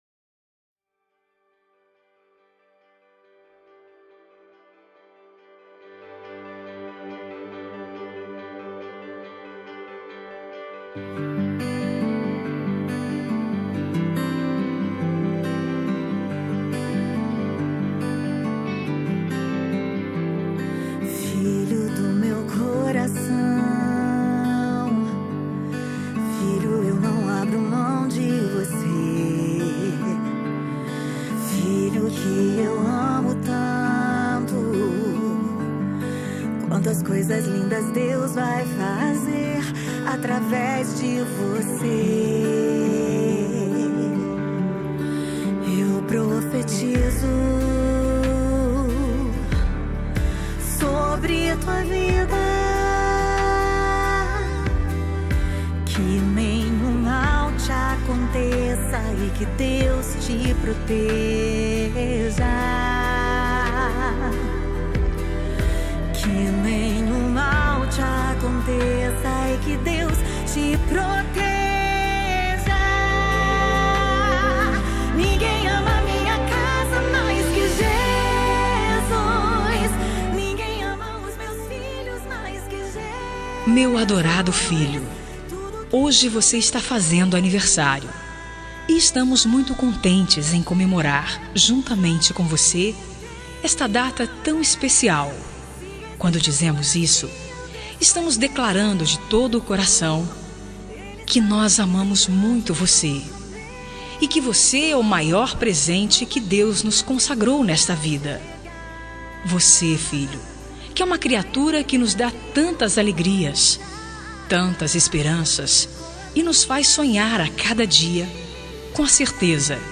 Aniversário de Filho – Voz Feminina – Cód: 5206 – Plural
5206-aniver-filho-fem.m4a